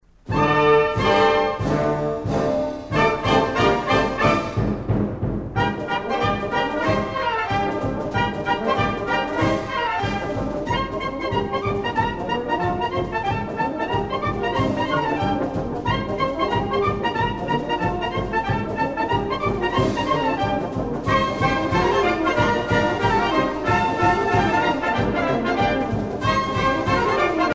danse
Pièce musicale éditée